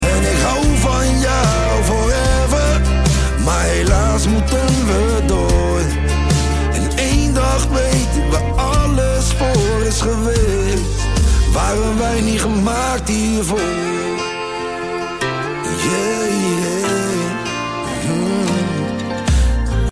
Met een krachtige, meeslepende sound en een eerlijke